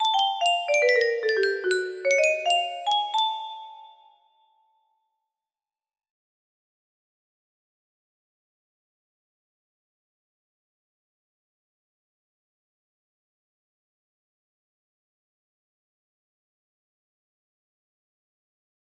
2222 music box melody